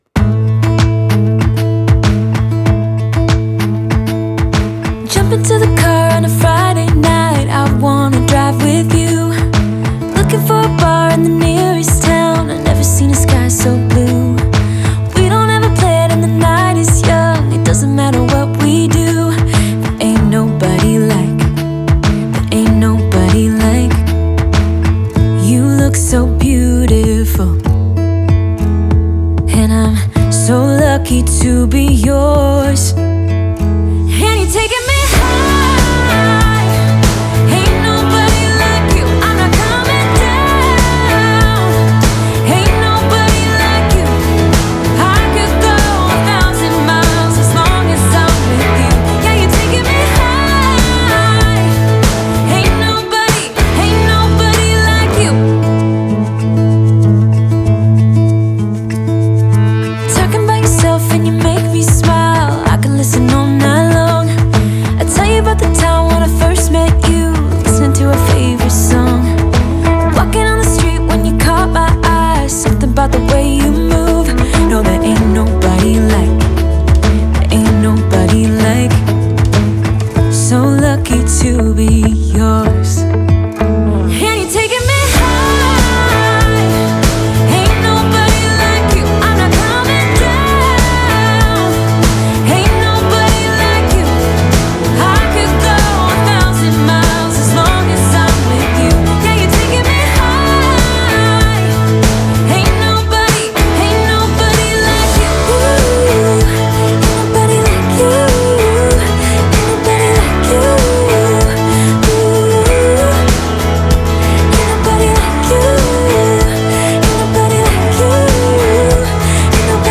★★new 静かな女性ボーカルで始まり、後半はミドルテンポで広がりのある曲です。
女性Vo 03：00